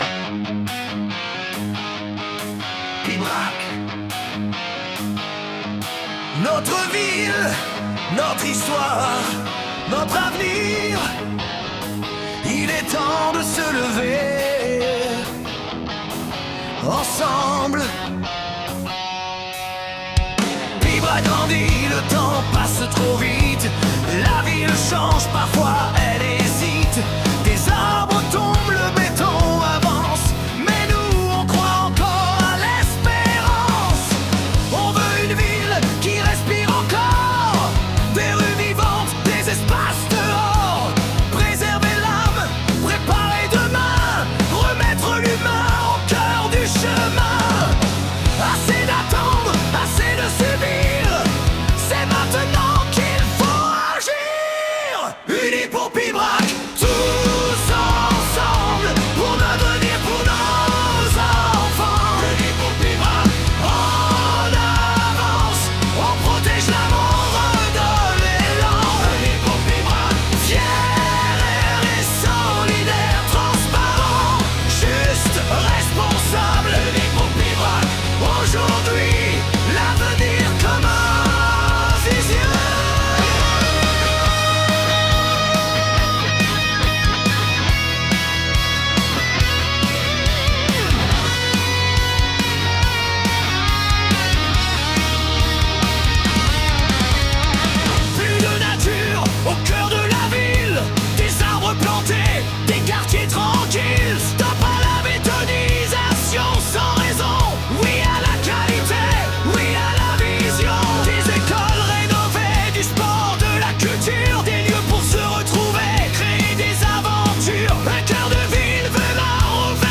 unis-pour-pibrac-version-hard-rock-LemEed2xWkvMYlW3.mp3